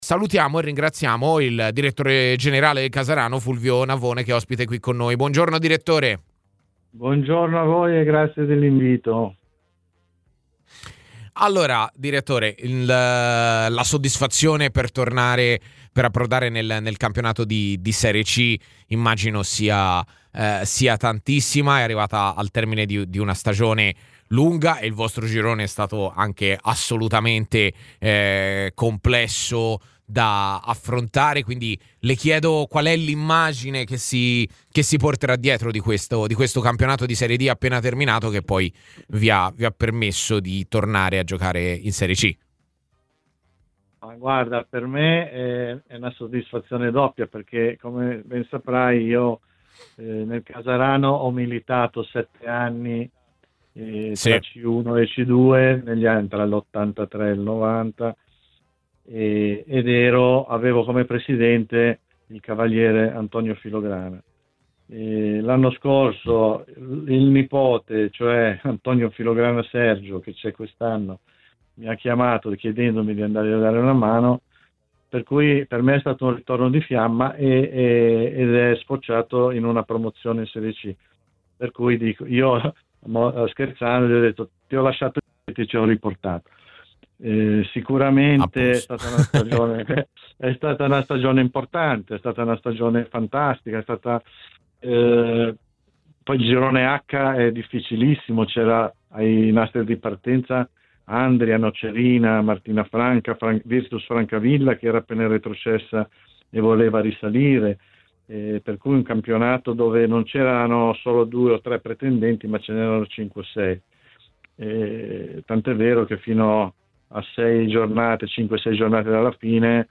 Ascolta l'audio Ospite dei microfoni di TMW Radio, all'interno della trasmissione 'A Tutta C'